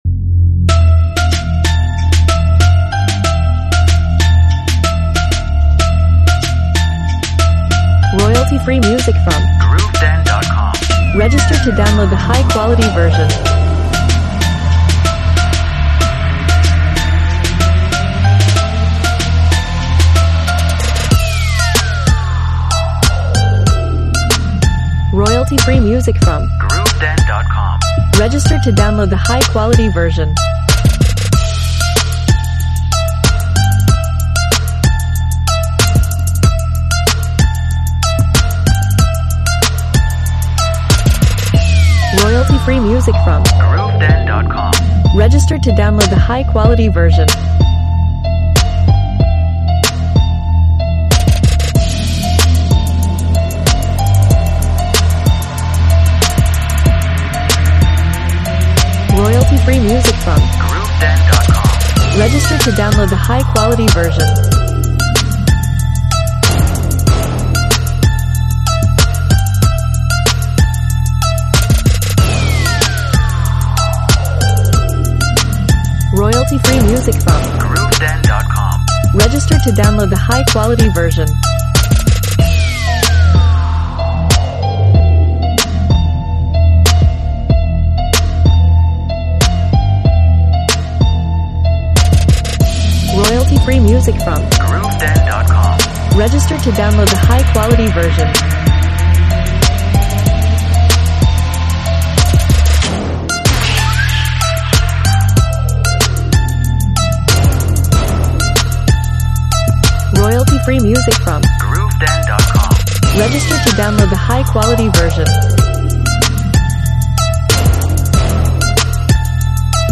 Modern urban track with powerful dynamics.
Instruments: Synth, bells , percussion, pad, drums, effects.